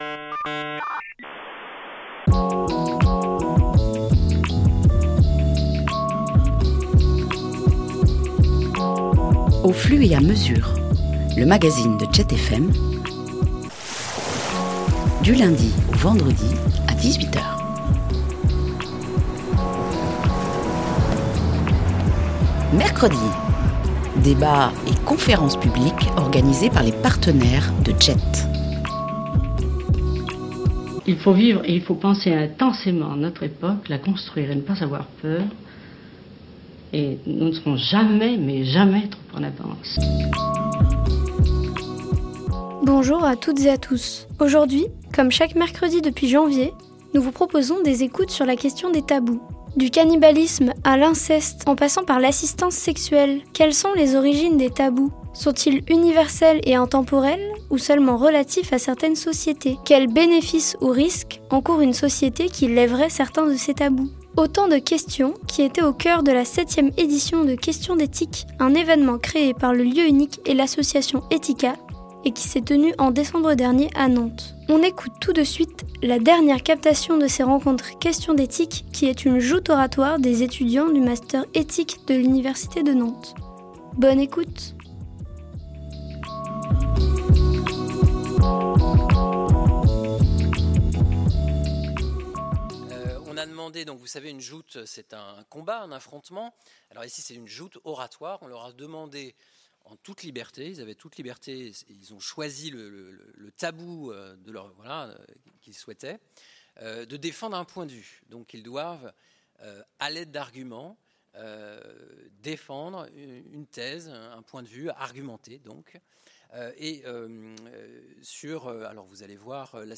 mp3_Questions-dethique_joute-oratoire.mp3